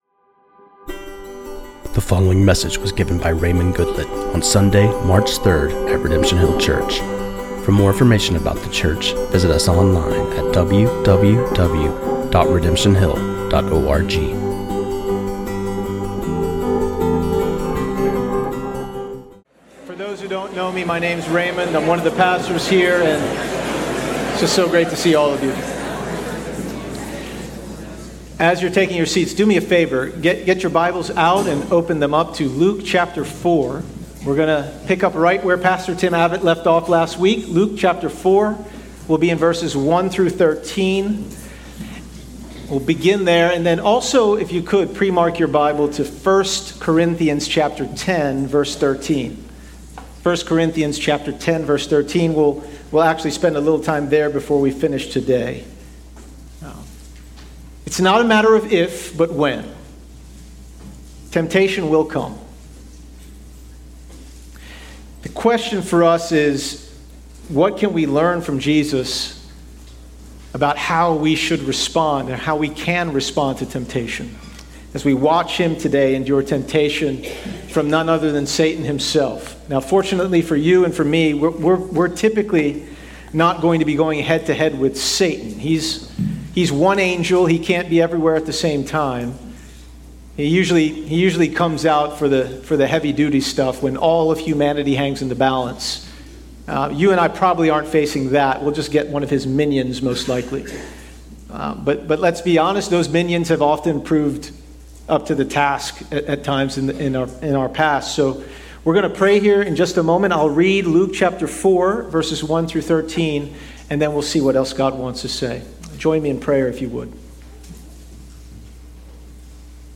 This sermon on Luke 4:1-13